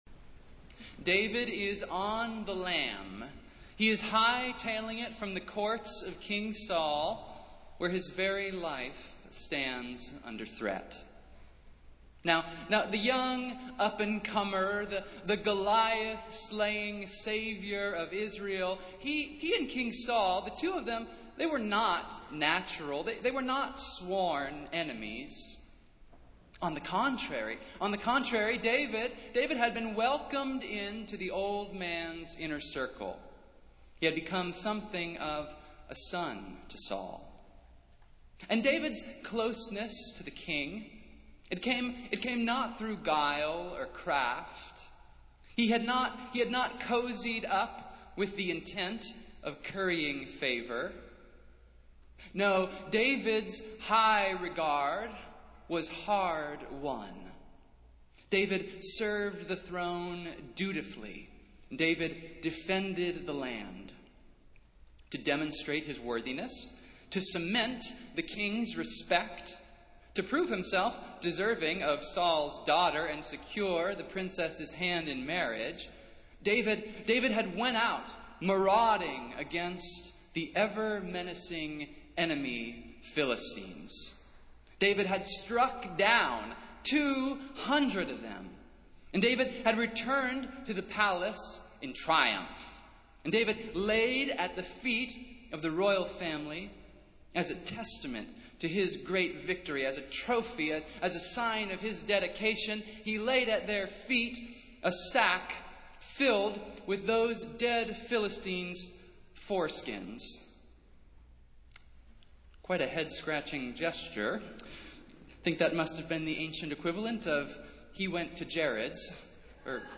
Festival Worship - Fourth Sunday in Lent